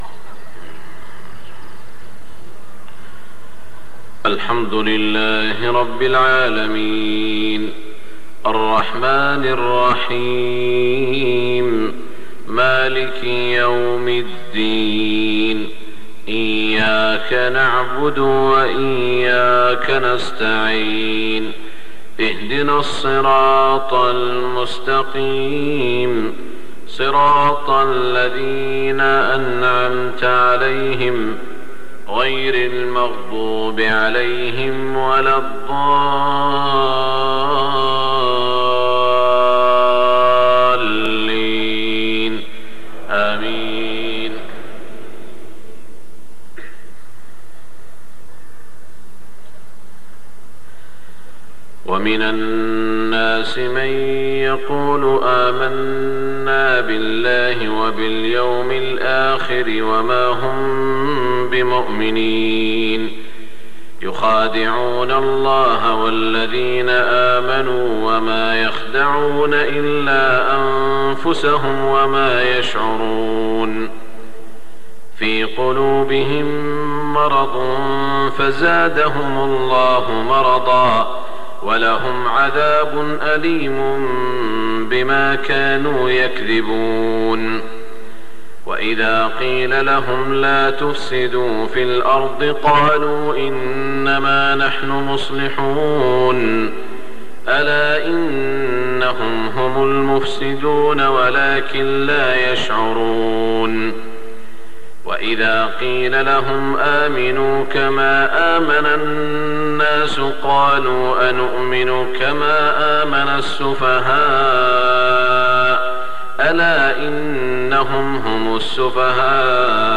صلاة الفجر 1423 من سورة البقرة > 1423 🕋 > الفروض - تلاوات الحرمين